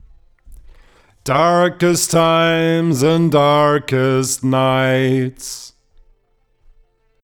Jetzt fängt Newtone mir in FL 20 aber an mir meine Samples regelrecht zu zerhacken. Als ob ich einen Gate Effekt drauf hätte.